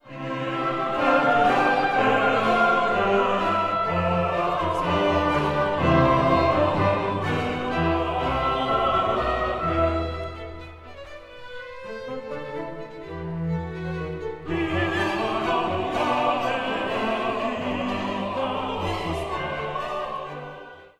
The skimpy countersubject and everpresent orchestral trill dull what is otherwise a joyous, lilting sound.
And if that weren’t enough, there are two more long annoying orchestra interludes.
Naxos version, 5 seconds instead of 25.